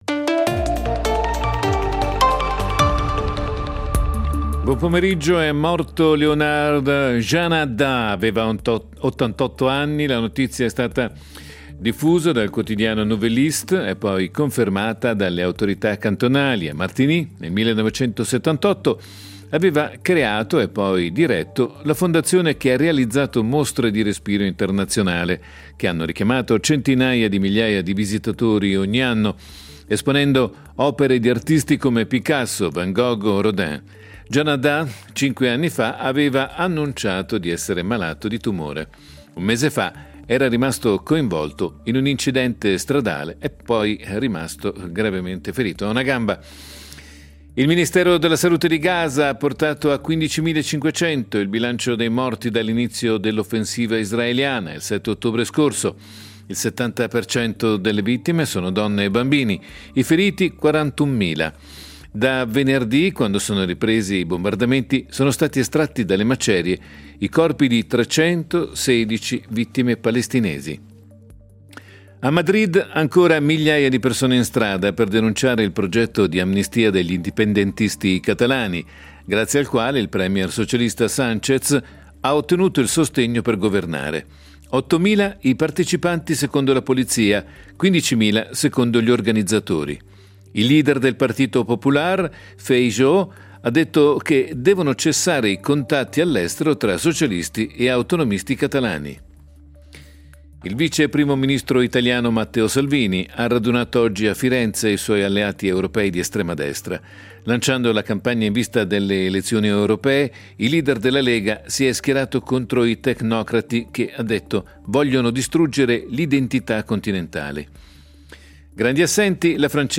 Notiziario delle 17:00 del 03.12.2023